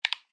button_sound_04.mp3